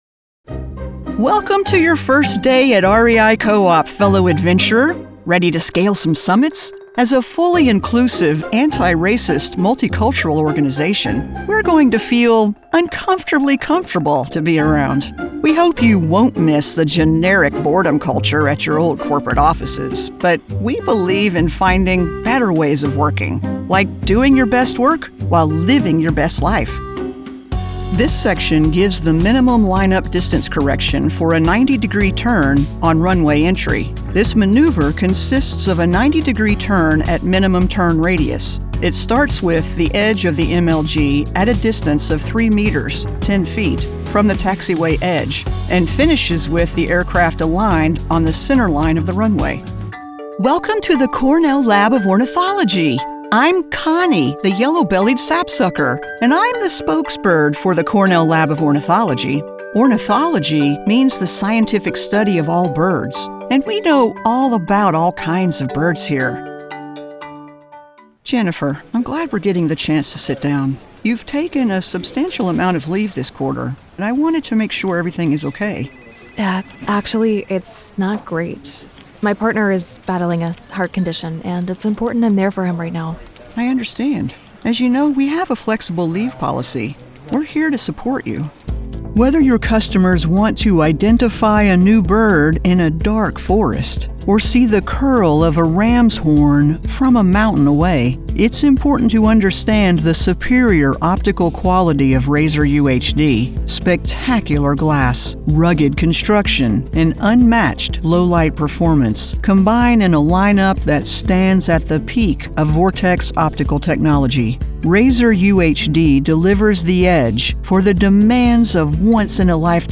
eLearning Demo
English - Southern U.S. English
Appalachian, Southern Georgia coast
Middle Aged